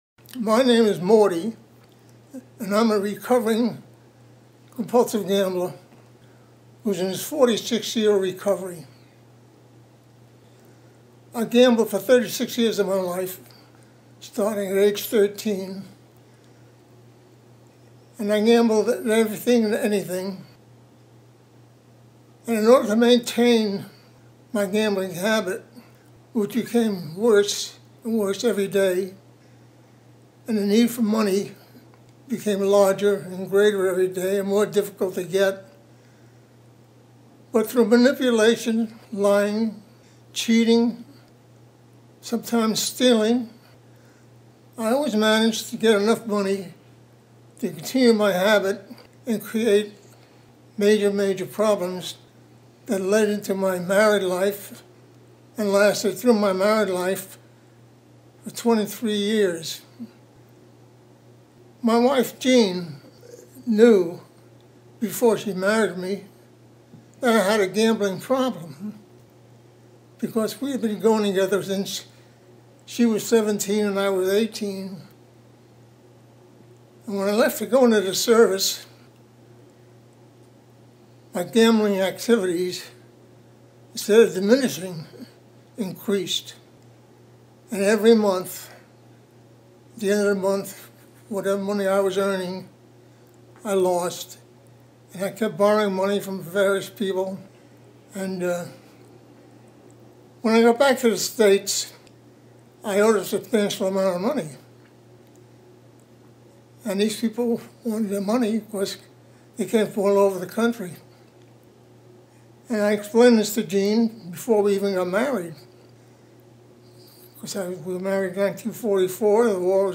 GA Speaker Audios